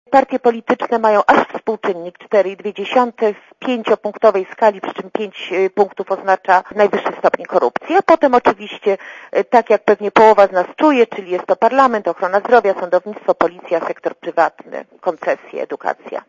Mówi Julia Pitera, szefowa Transparency Internatiional Polska